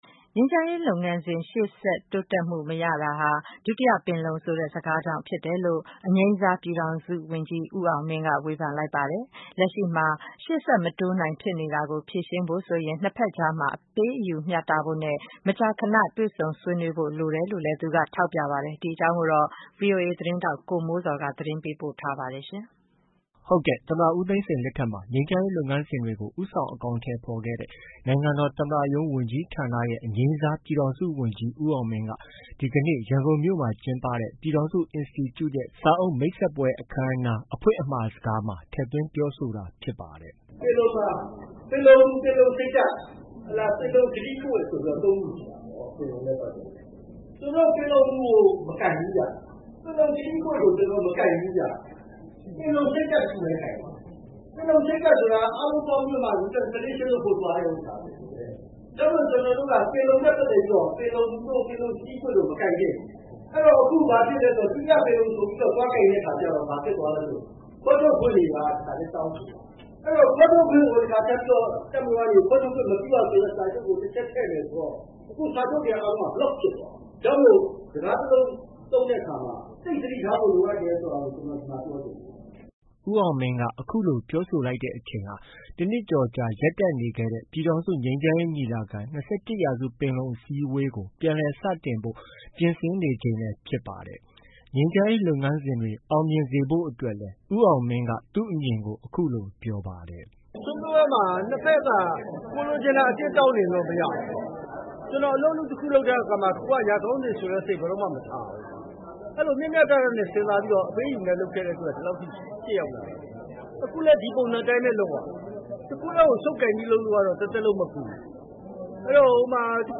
ပြည်ထောင်စုအင်စတီကျူရဲ့ စာအုပ်မိတ်ဆက်ပွဲ အခမ်းအနားမှာ အငြိမ်းစား ပြည်ထောင်စုဝန်ကြီး ဦးအောင်မင်း အဖွင့်အမှာစကားပြော